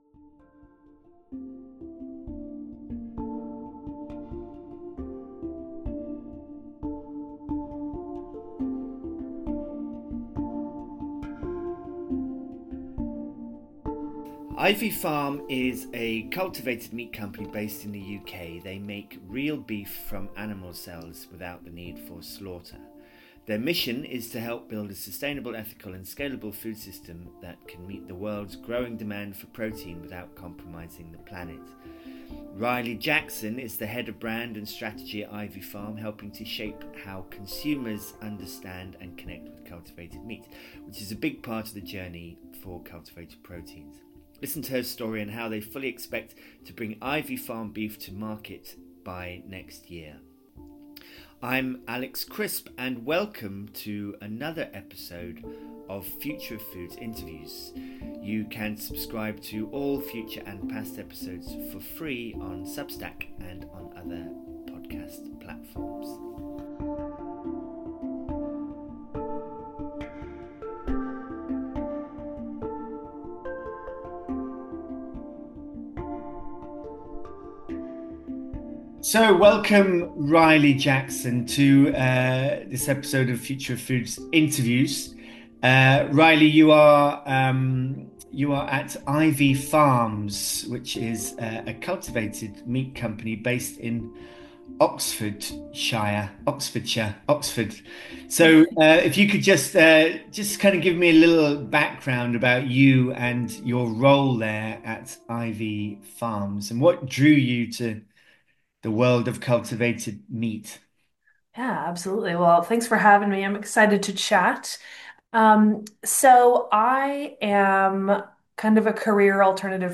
Investing in the future of alt protein and 'Noochies' too - Cult Food Science – Future of Foods Interviews - Alt Proteins, Cell Agriculture, an End to Factory Farming.